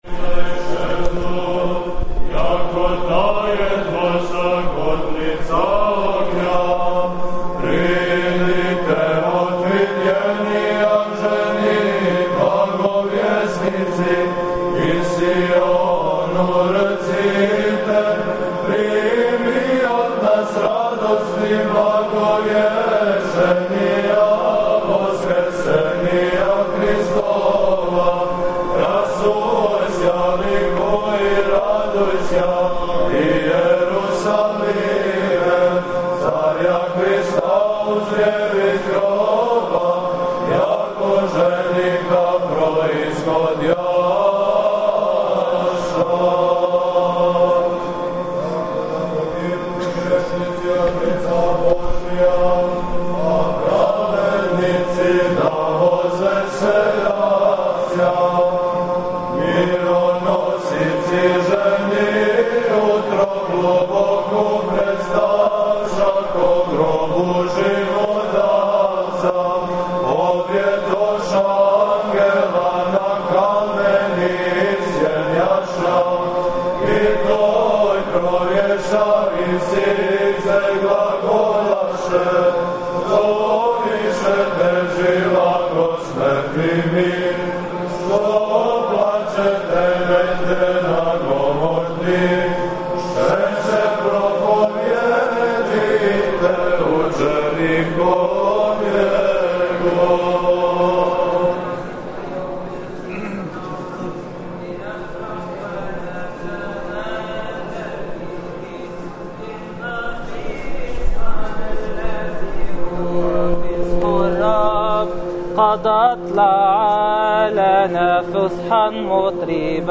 Пасхално Вечерње у Саборном храму у Новом Саду | Епархија Бачка
Входом архијереја у цркву Светог великомученика Георгија и појањем васкршњег тропара започето је торжествено славословље.
Пасхалне стихире - поју монаси Светоархангелског манастира у Ковиљу и хор студената Православног богословског факултета у Београду Фотографије